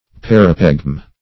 Search Result for " parapegm" : The Collaborative International Dictionary of English v.0.48: Parapegm \Par"a*pegm\, n. [L. parapegma, Gr.